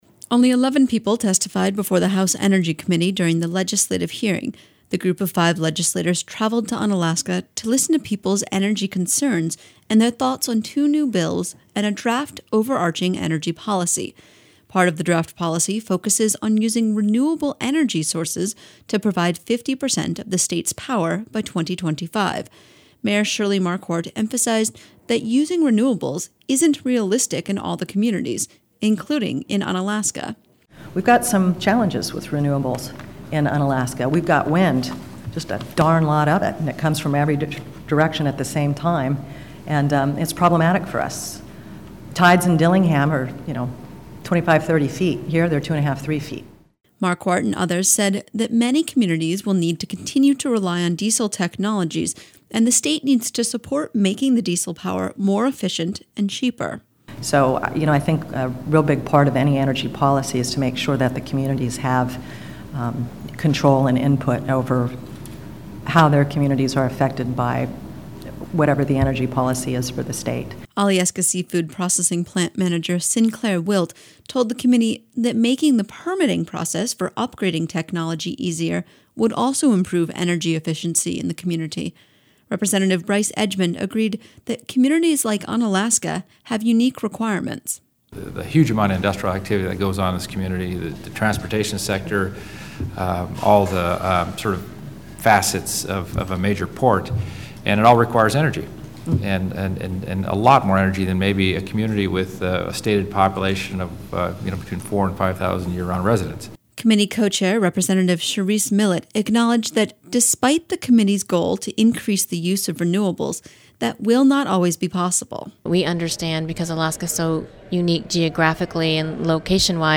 Unalaska, AK – Unalaska hosted a House Energy Committee meeting Wednesday evening. As the committee sought input on a new energy policy for the state, they heard that sometimes alternative energy isn't the best alternative at all.